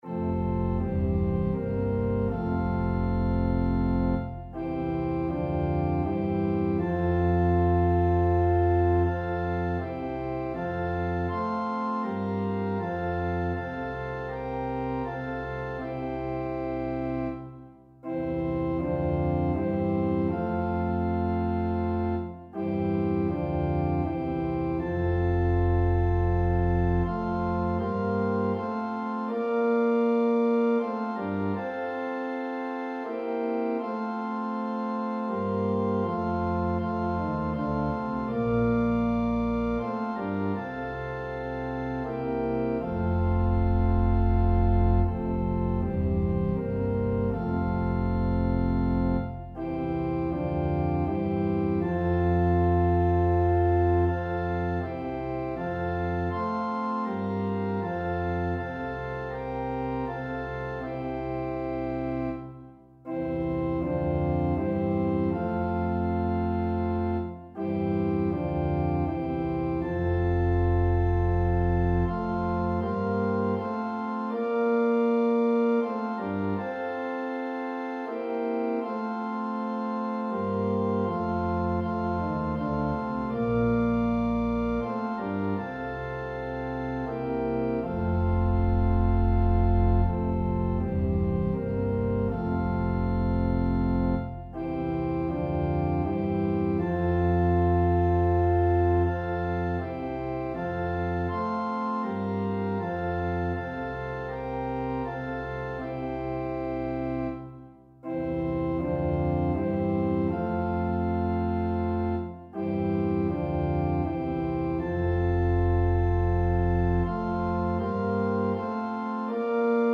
Click the Button to sing the prayer in F, or play the song in a New Window